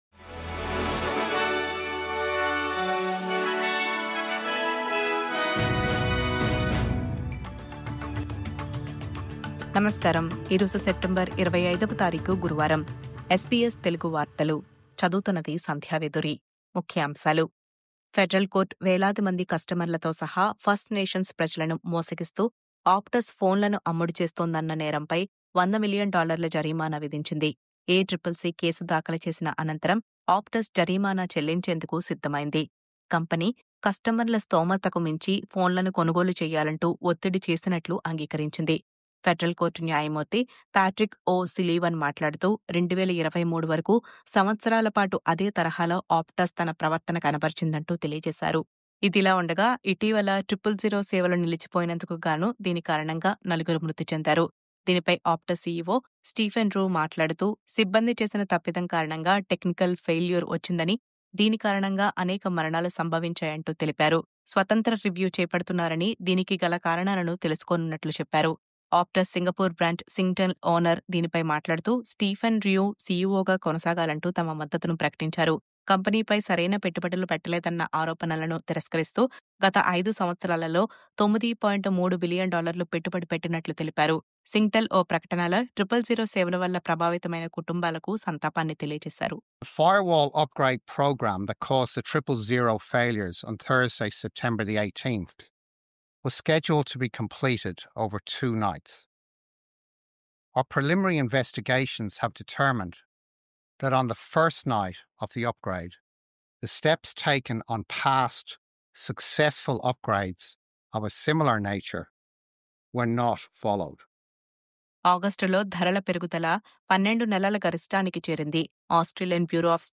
News update: కస్టమర్లను మోసం చేసిన Optus‌కు భారీ $100 మిలియన్ జరిమానా..